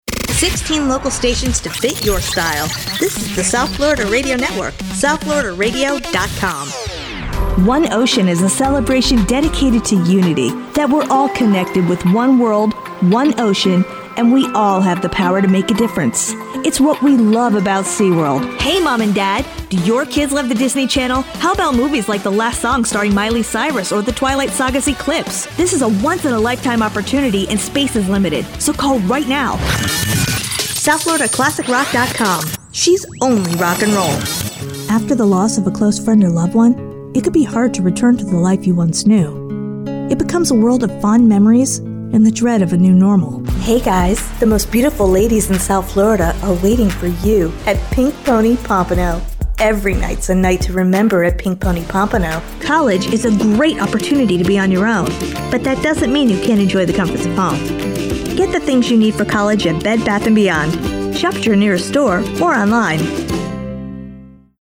Voiceovers Demo
American English (Neutral), American English (Southern)